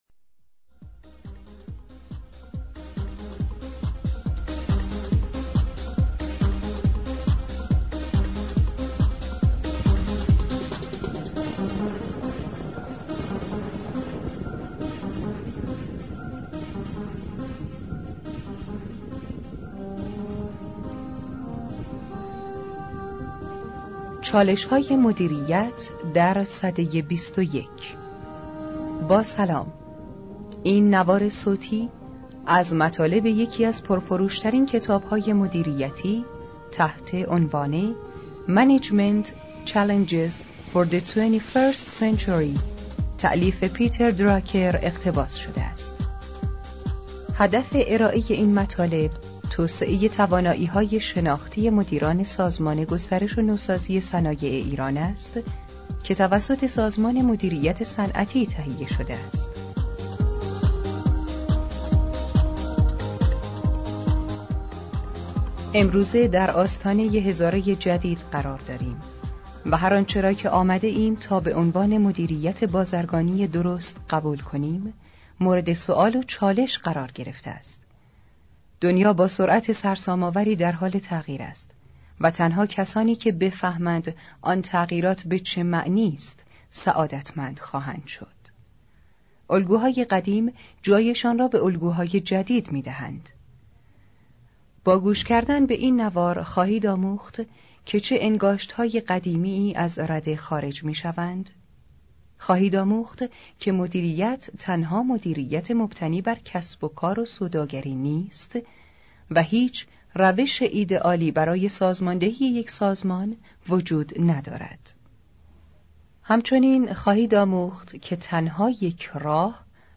مصاحبه با پیتر دراکر.mp3
مصاحبه-با-پیتر-دراکر.mp3